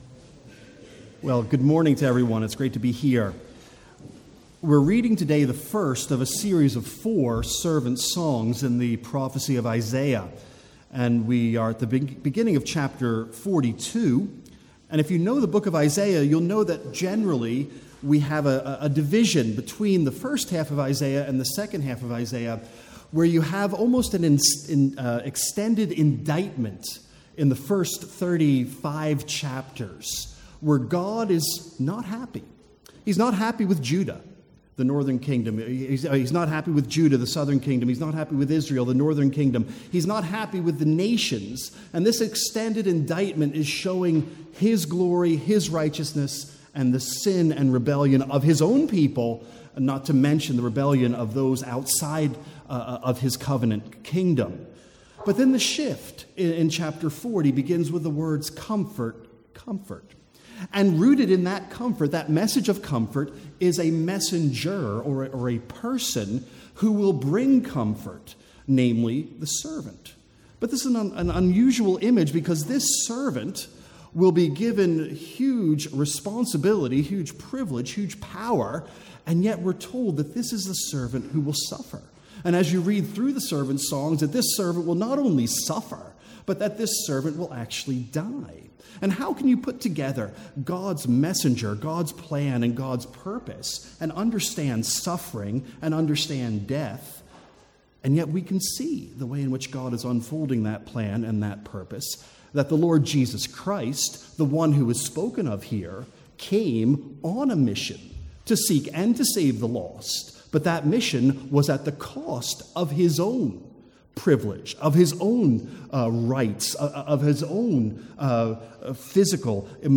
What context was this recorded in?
From the Sunday morning series in the Servant Songs of Isaiah.